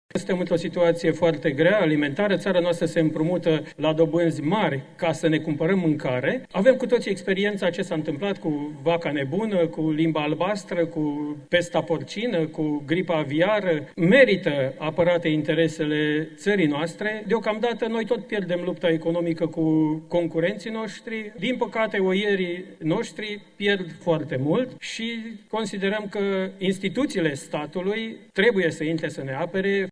Deputatul AUR Dumitru Flucuş spune că, în forma adoptată, documentul permite inclusiv, exterminarea animalelor sănătoase de către crescătorii care vor să obţină acest sprijin din partea statului: